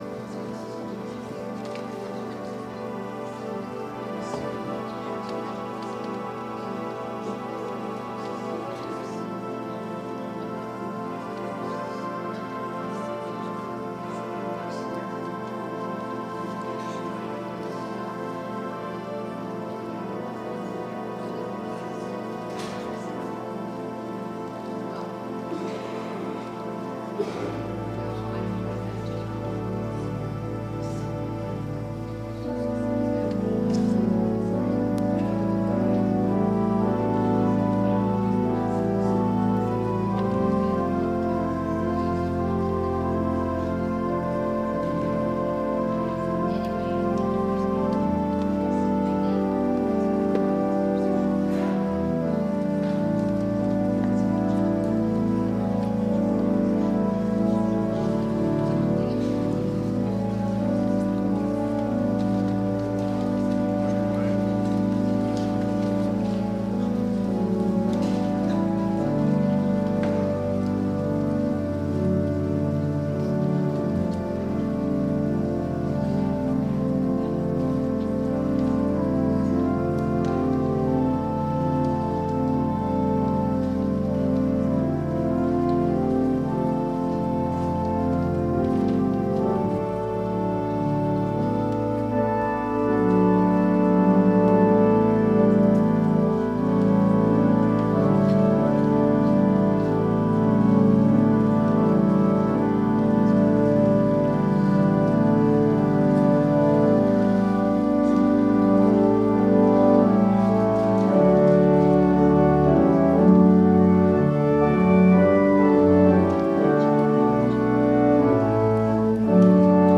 WORSHIP - 10:30 a.m. Seventh after Epiphany